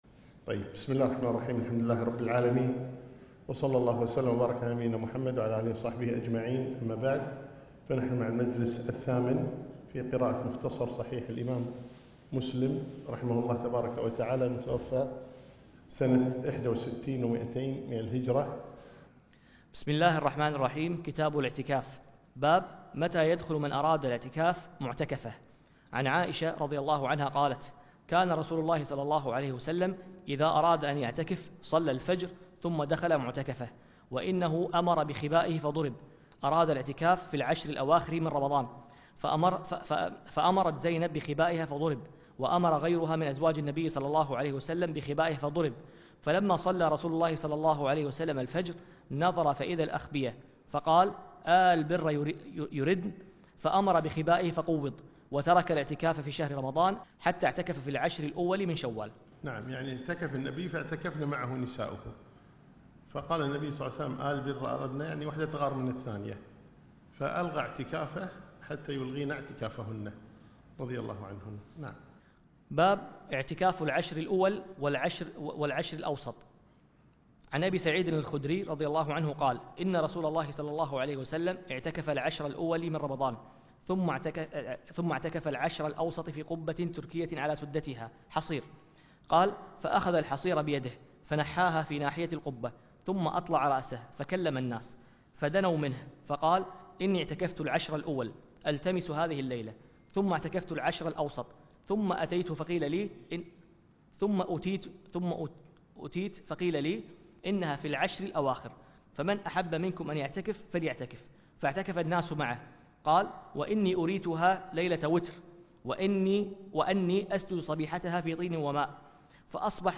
الحديث وعلومه     قراءة فى كتب الصحاح